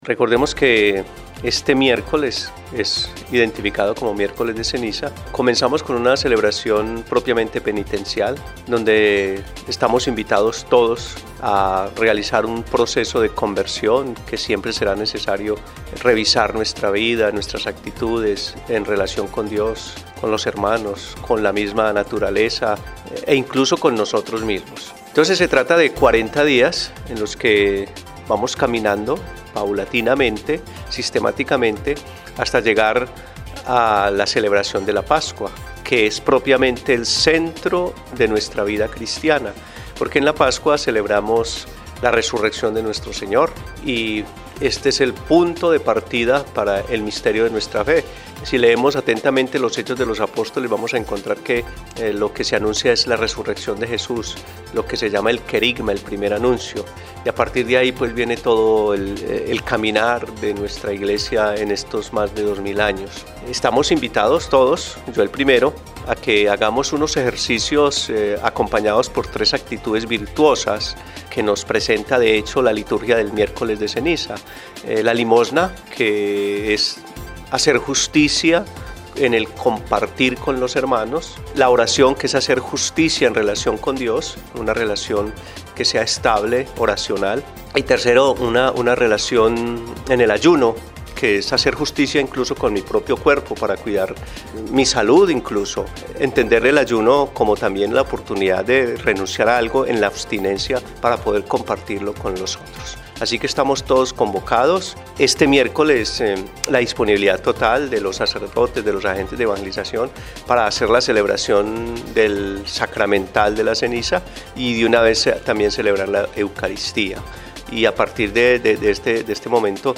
Monseñor Omar de Jesús Mejía Giraldo, Obispo de la Arquidiócesis de Florencia, explicó que esta época es propicia para los procesos de conversión con todo lo que nos rodea, progresando hacía un mejor mañana.